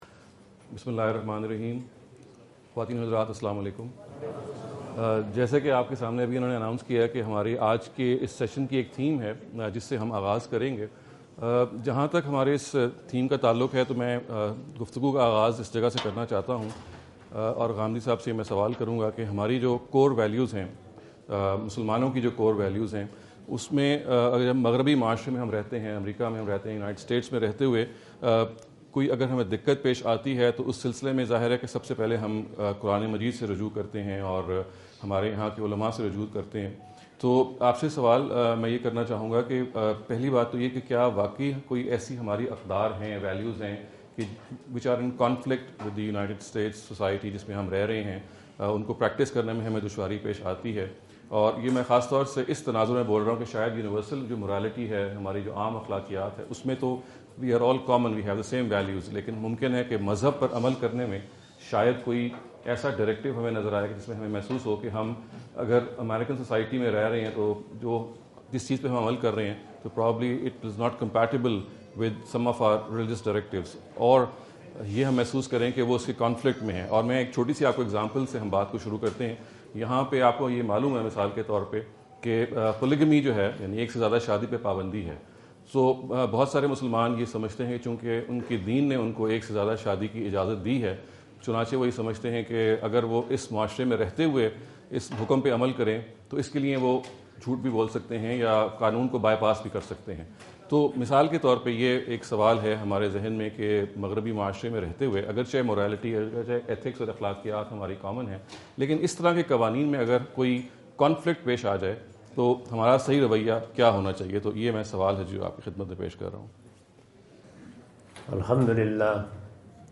Q&A Session US Tour 2017, Corona (Los Angeles)
This session consists of a discussion on the core values to be upheld by Muslims in western societies. The rest of the video focuses on general questions and answers on various topics.